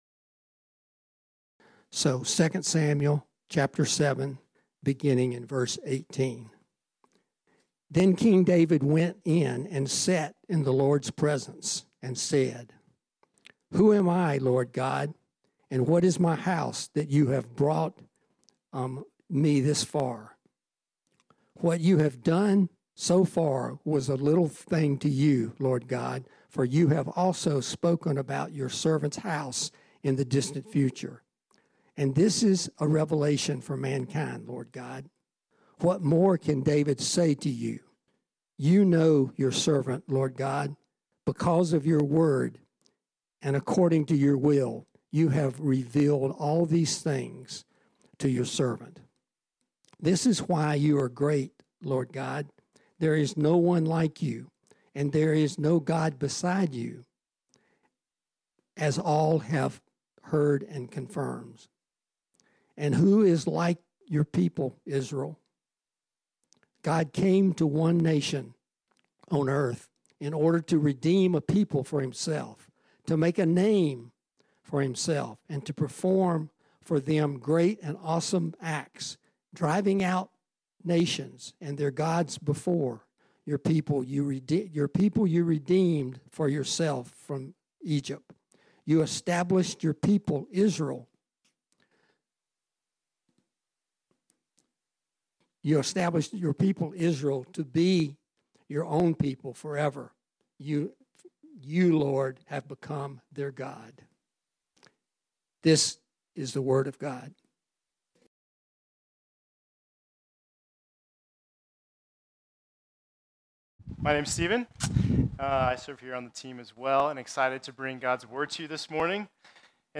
This sermon was originally preached on Sunday, July 16, 2023.